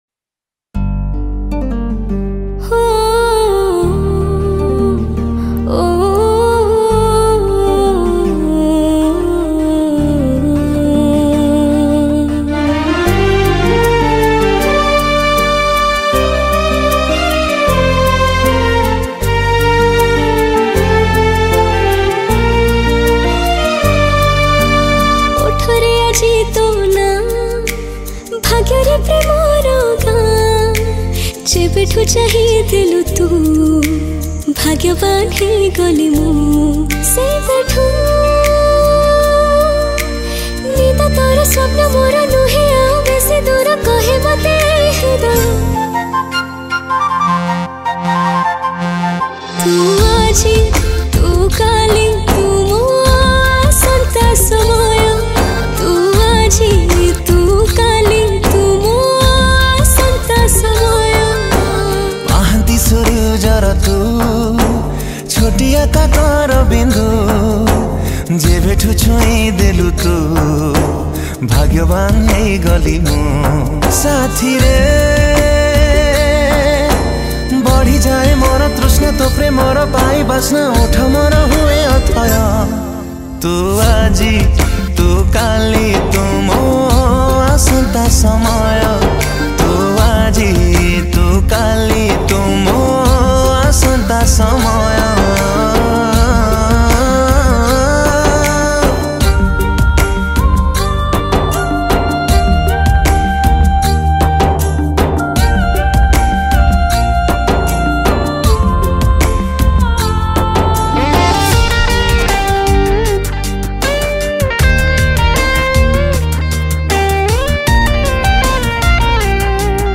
Keyboard
Guitarist